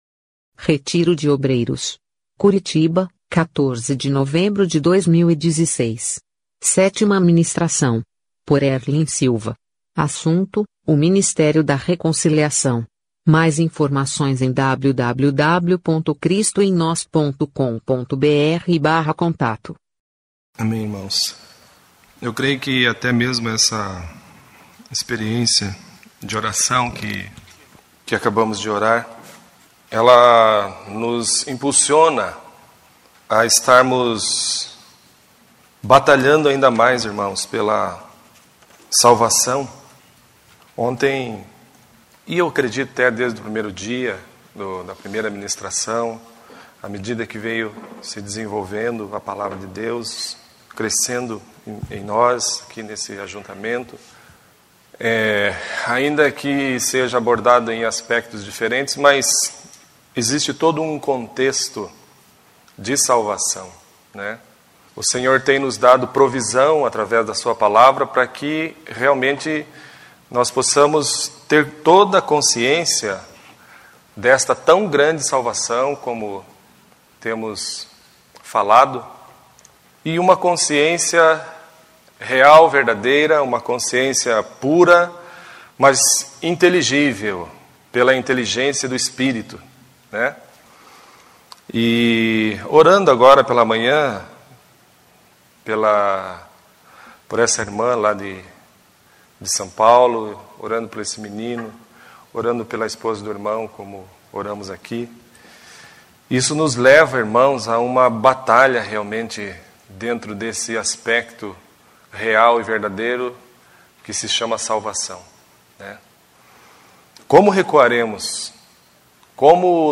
Retiro de obreiros em Curitiba – Novembro/2016